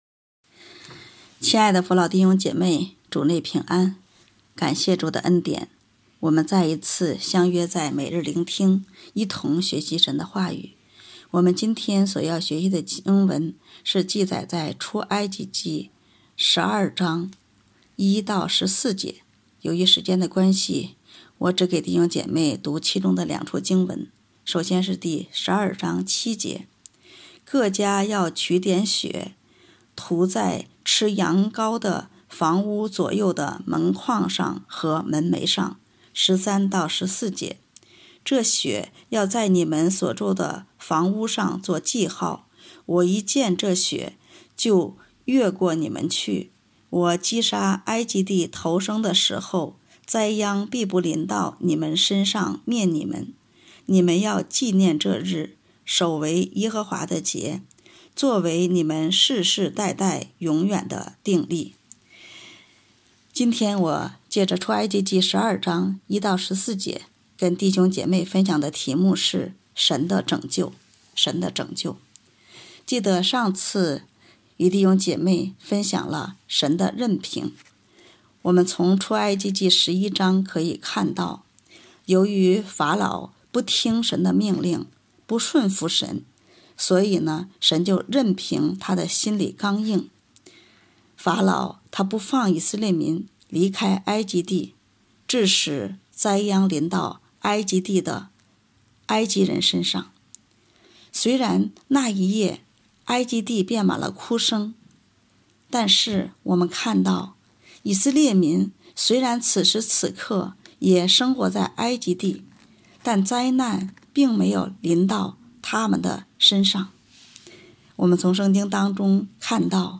北京基督教会海淀堂